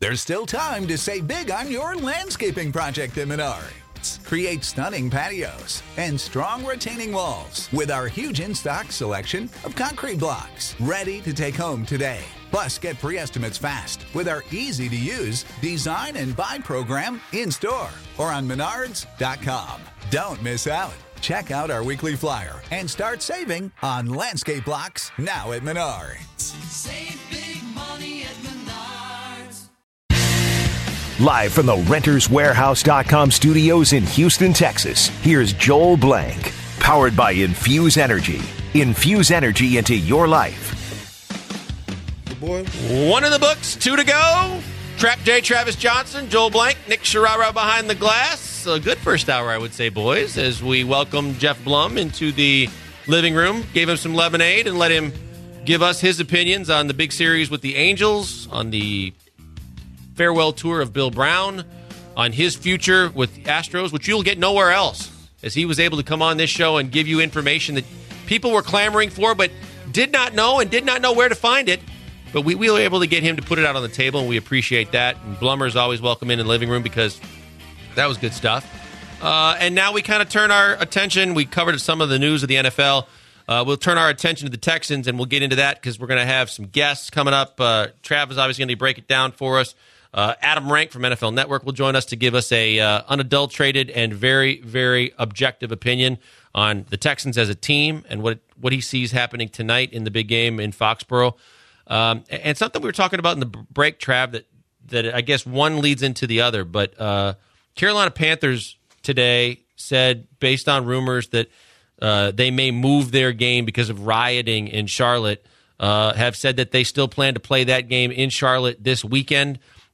Does Skip Bayless have any credibility? The guys take call and debate whether JJ Watt is over-rated and what Draft pick he’d be.